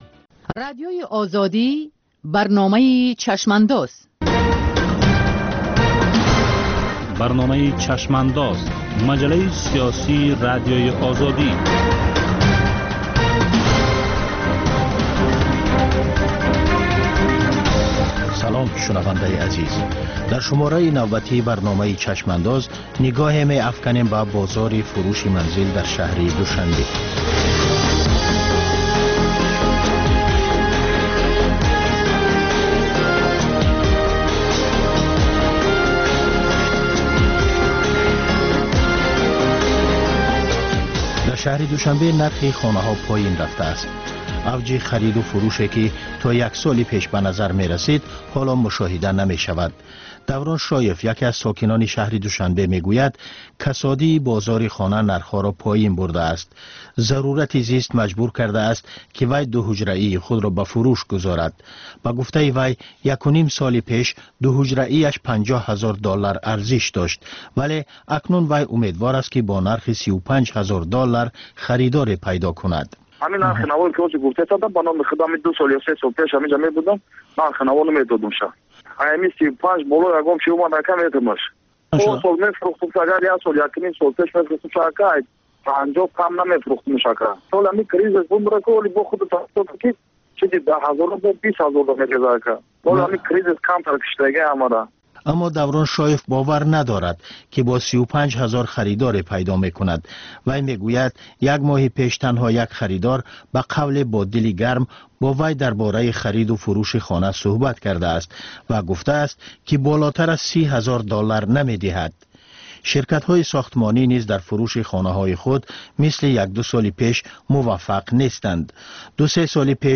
Баррасӣ ва таҳлили муҳимтарин рӯйдодҳои сиёсии рӯз дар маҷаллаи "Чашмандоз". Гуфтугӯ бо коршиносон, масъулини давлатӣ, намояндагони созмонҳои байналмилалӣ.